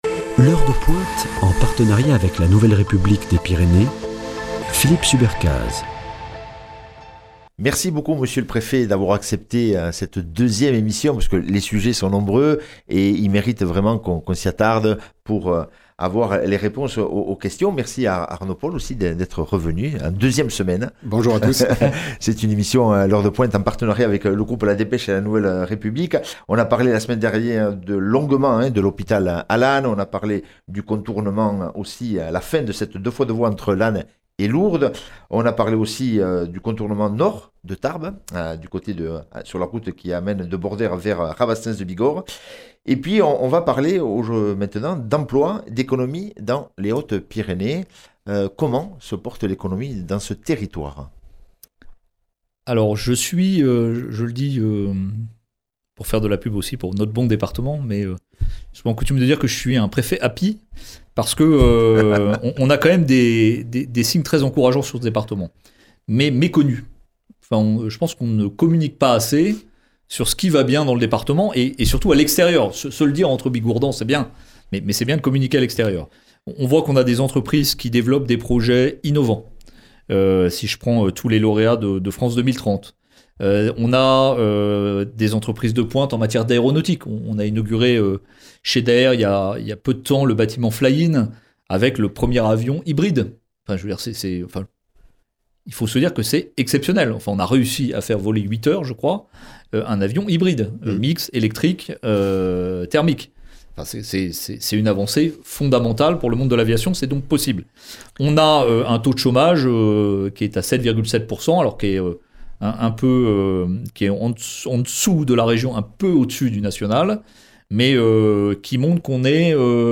Deuxième émission avec le Préfet des Hautes-Pyrénées, M. Jean Salomon : toutes les réponses à vos questions !